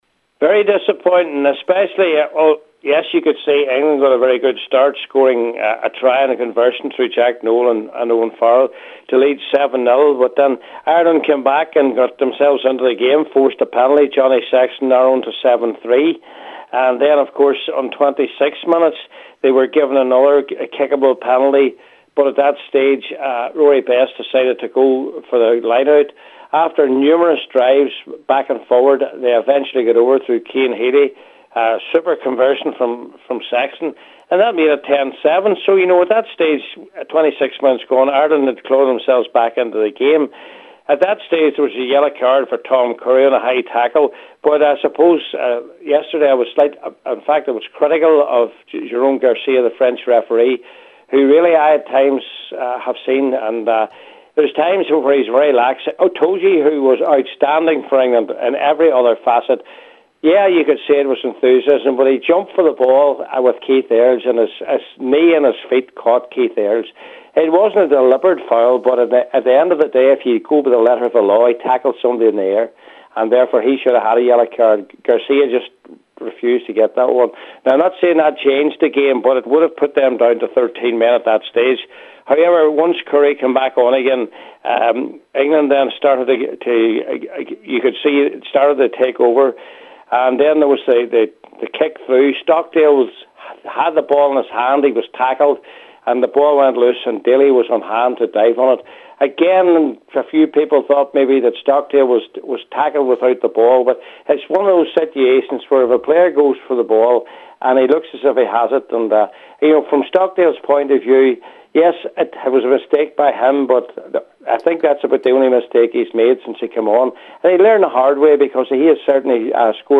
reviewed the weekend’s rugby action on Sunday Sport…